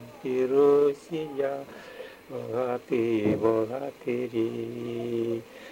Произношение фрикативного /ɣ/ вместо смычного /г/